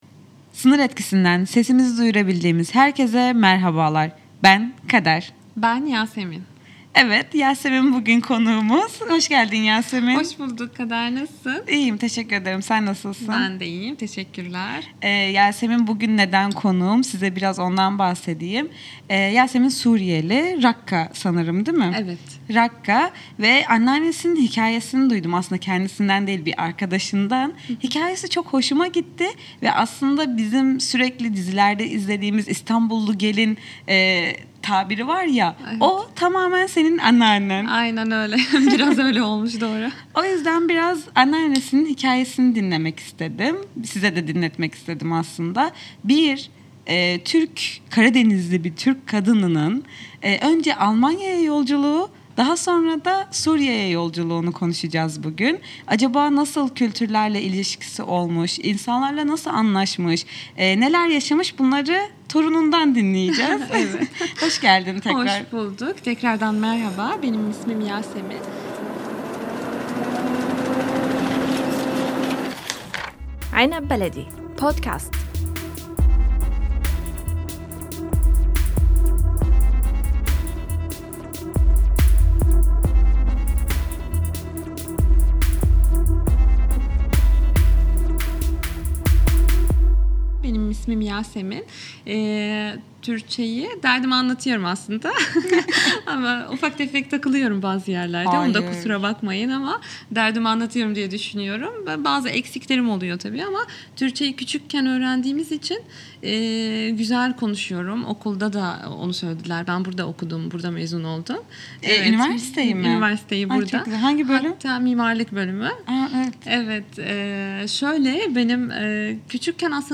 Hangi ülkeye giderse gitsin entegre olabilmiş ve Türk kimliğini unutmamış, unutturmamış. Sohbetimiz koyu.